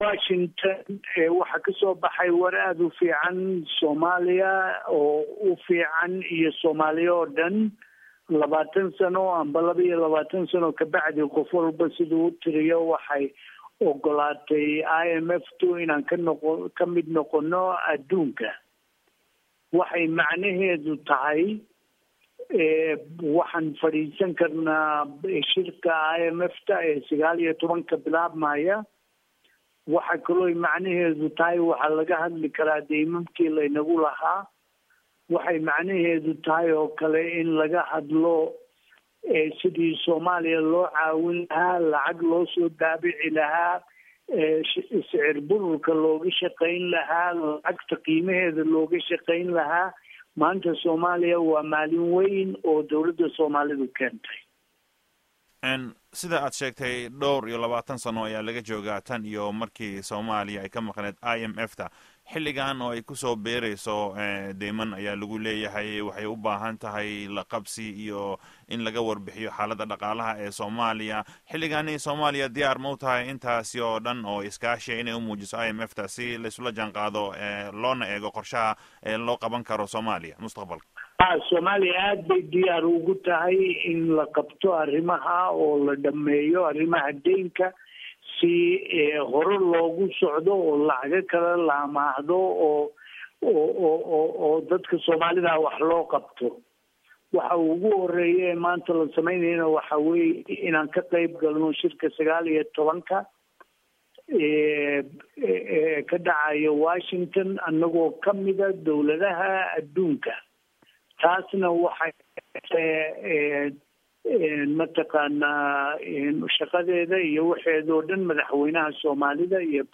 Dhageyso Wareysiga Guddoomiyaha Baanka Dhexe